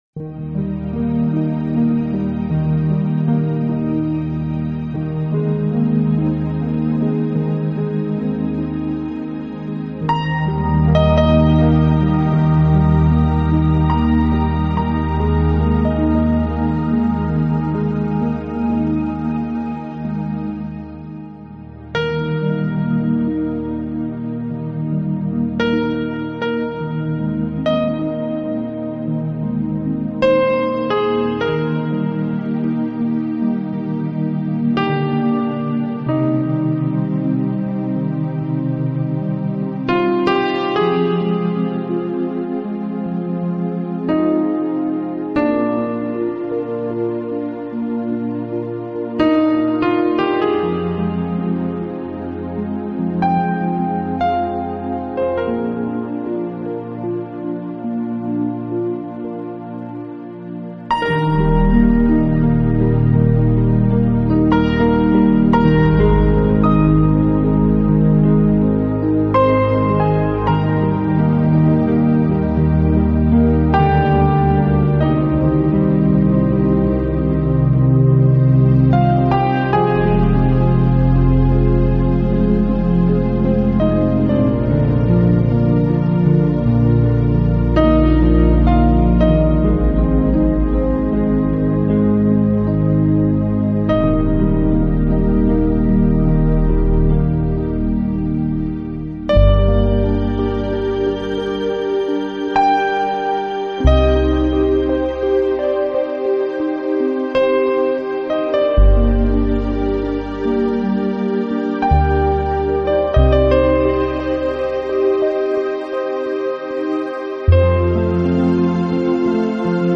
音乐流派：New Age